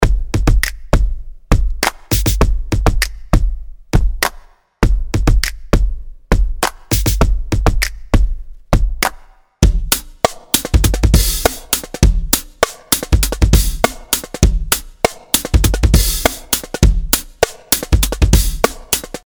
Für die nächsten Audiodemos habe ich wieder einen Groove aus dem Repertoire von Toontracks EZDrummer 2 verwendet, Kit und MIDI-Groove entstammen den Claustrophobic-Erweiterungspaketen. Die Bassdrum habe ich bereits mit der PunchBox ersetzt, hier noch ohne Bit-Crusher: